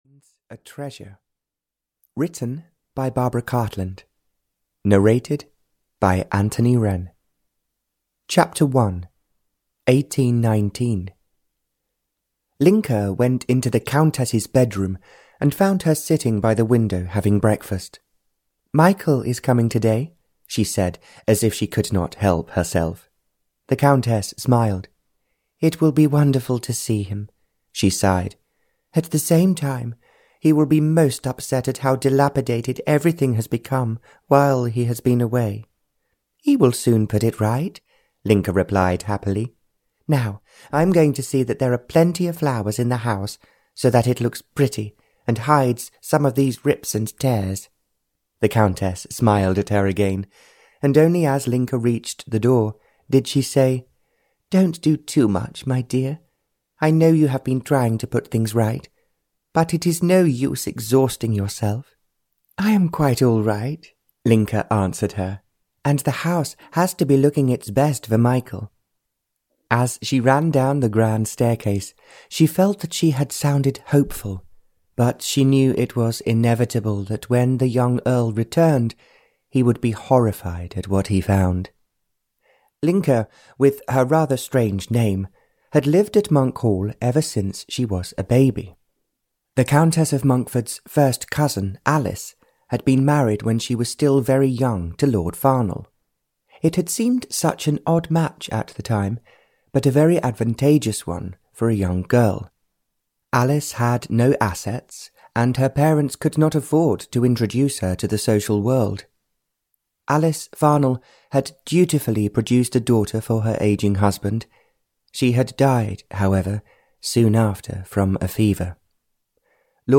Audio knihaLove Finds a Treasure (Barbara Cartland's Pink Collection 151) (EN)
Ukázka z knihy